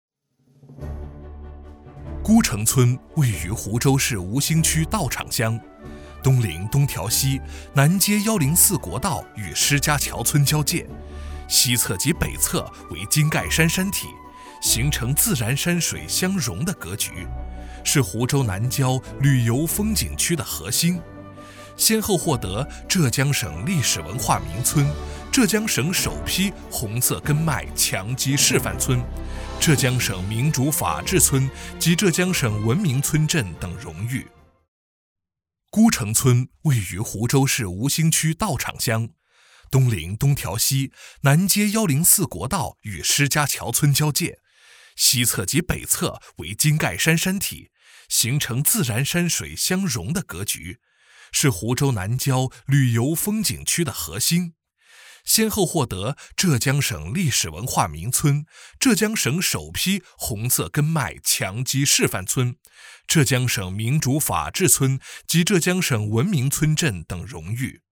【专题】大气明亮 菰城村
【专题】大气明亮  菰城村.mp3